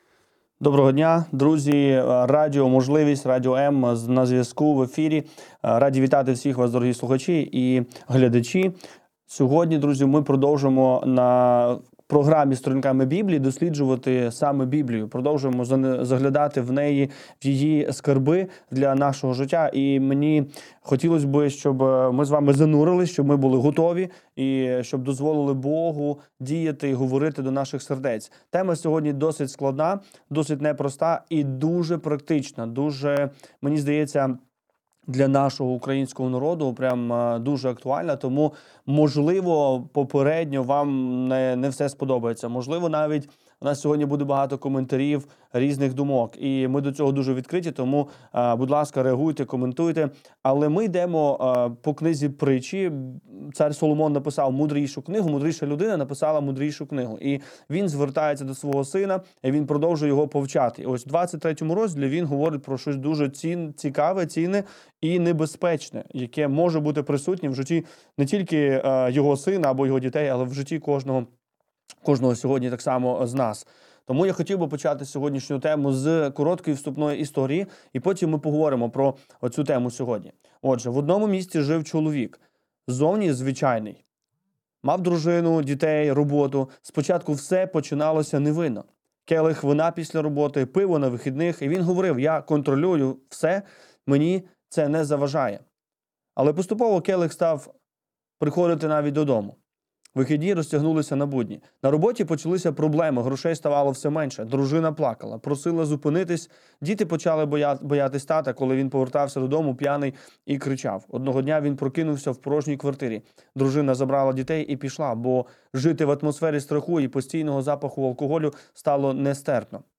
Ефір програми Сторінками Біблії Чи забороняє Біблія алкоголь!?